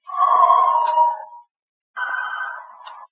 banshee-scream1.mp3